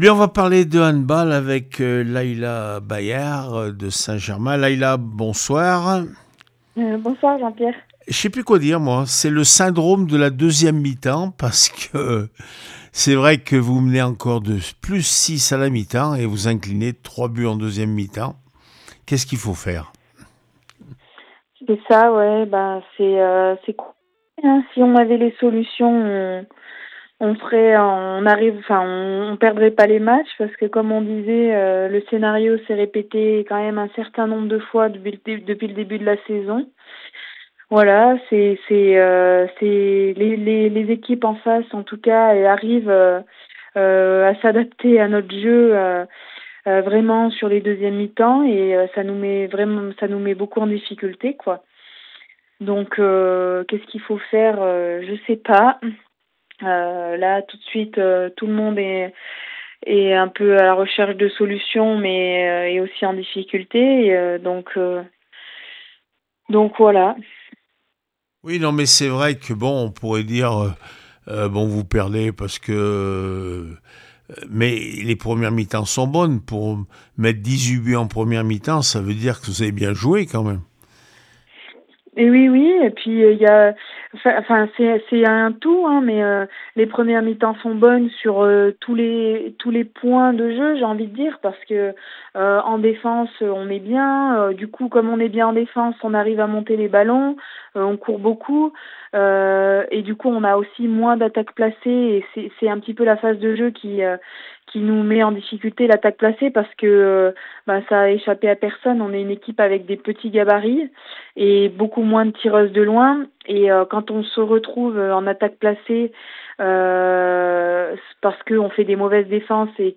21 avril 2026   1 - Sport, 1 - Vos interviews
handball n2f st Chamond 360-27 st germain blavozy réaction après match